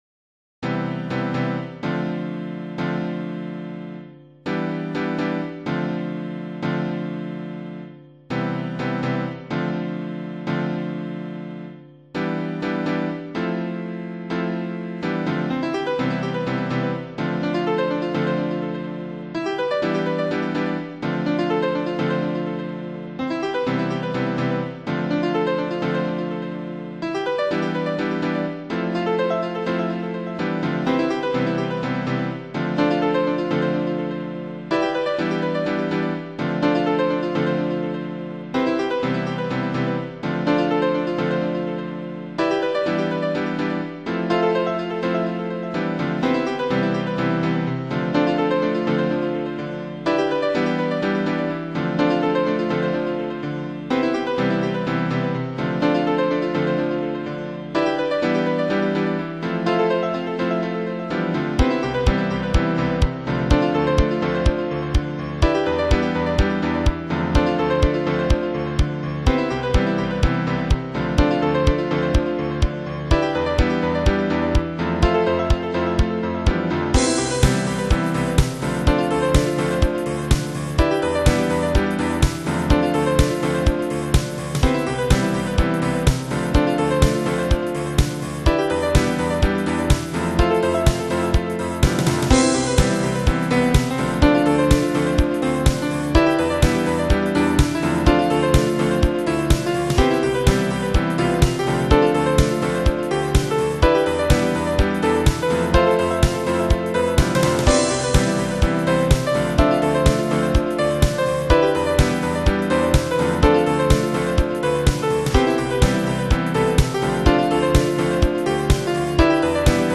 ピアノ狂想曲（？）
音色やオクターブを変えただけの二番煎じです。
音がシンプルになった分、あらが目立ってしまいました（汗）   Roland VSC 3.2